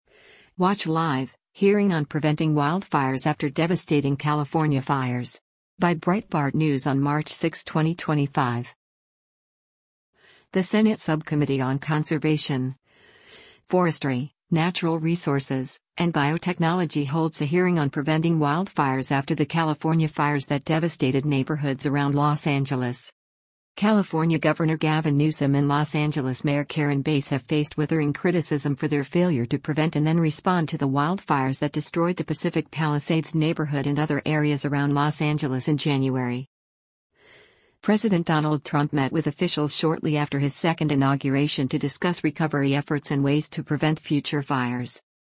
The Senate Subcommittee on Conservation, Forestry, Natural Resources, and Biotechnology holds a hearing on preventing wildfires after the California fires that devastated neighborhoods around Los Angeles.